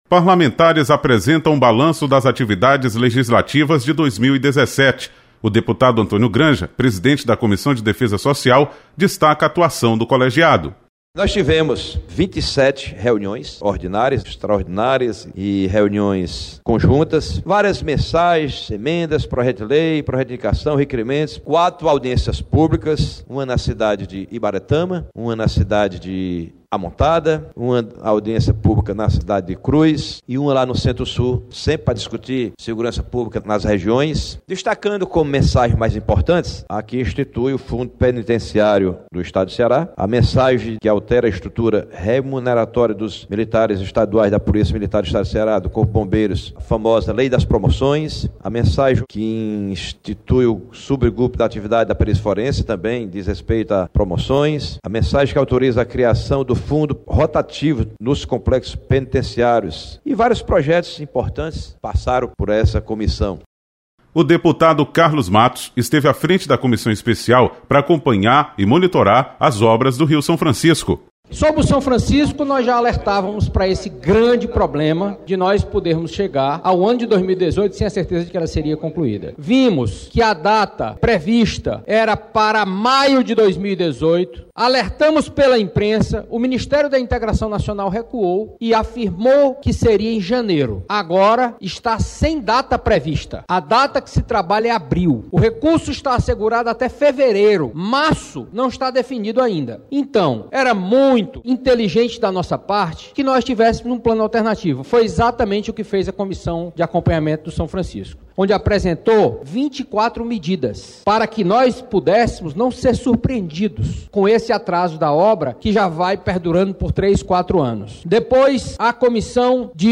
Parlamentares apresentam o balanço das atividades legislativas de 2017.